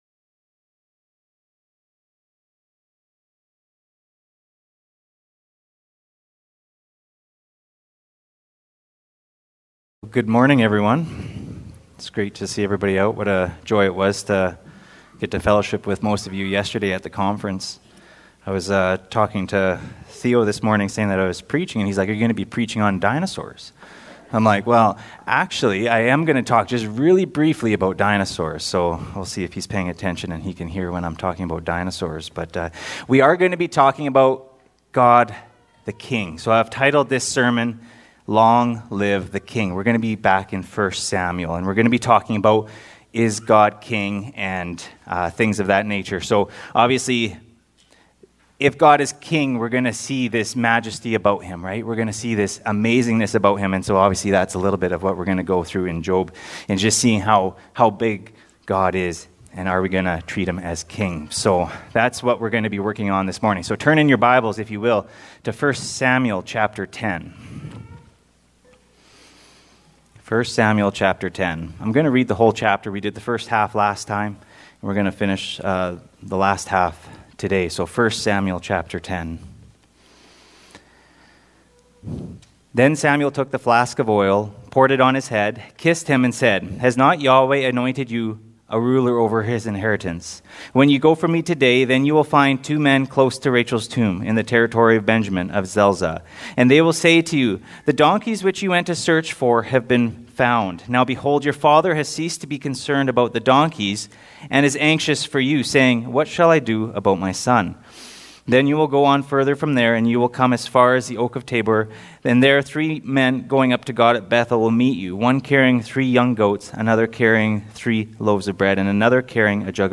Category: Pulpit Sermons